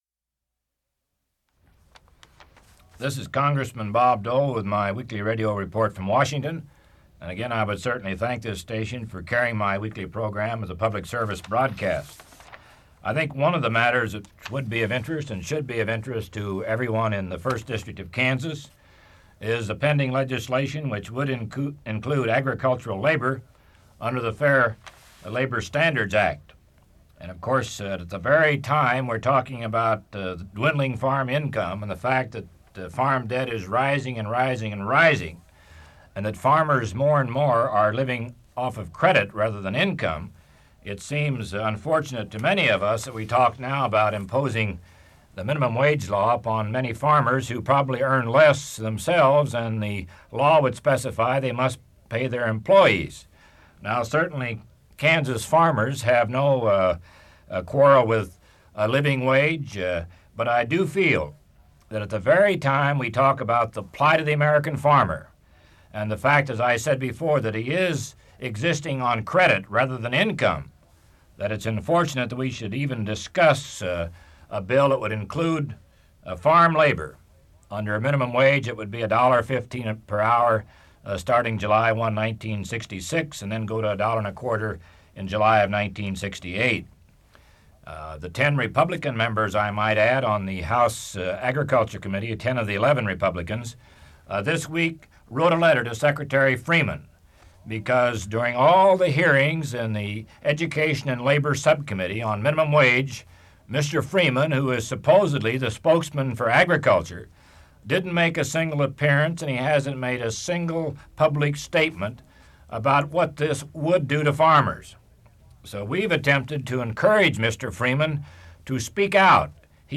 Part of Weekly Radio Report: Minimum Wage